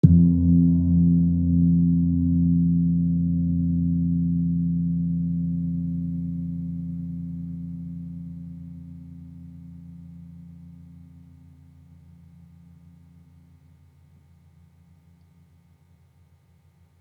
Gong-F1-f.wav